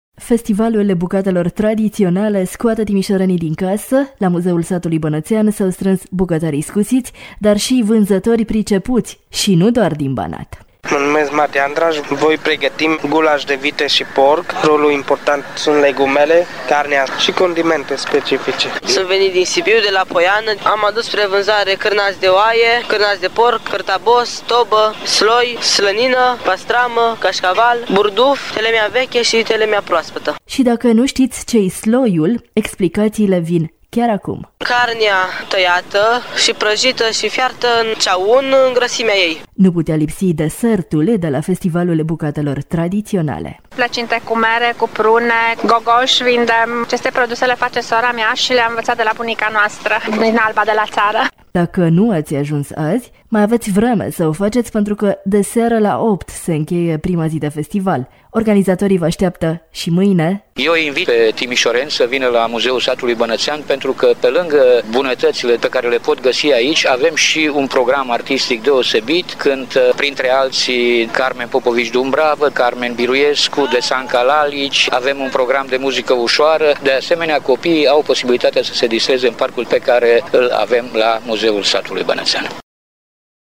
Timişorenii sunt invitaţi la acest sfârşit de săptămână la Zilele bucatelor tradiţionale, găzduite de Muzeul Satului Bănăţean.
Programul artistic cuprinde muzica populară, ușoară și muzica de fanfară.